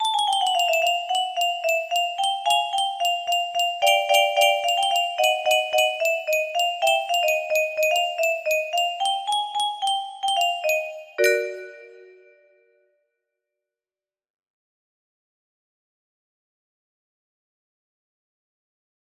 W.I.P (Circus themed ig?) music box melody